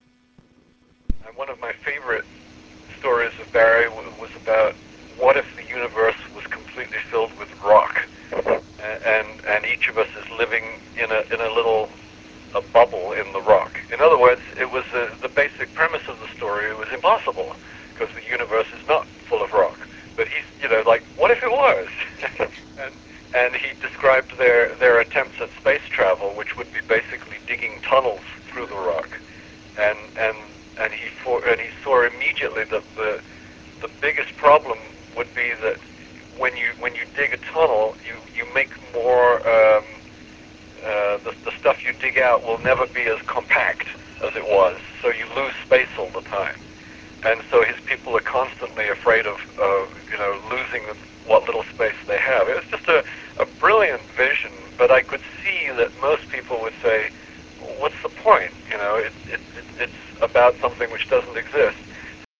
Charles Platt Interview, July 4 2000
I had the pleasure of asking him a few questions about his history with New Worlds, thoughts on the genre, publishing industry and fringe sciences as well as talking about his friend and once-collaborator, Barry Bayley. The interview was conducted on phone, from Helsinki to Arizona, on the 4th of July, 2000.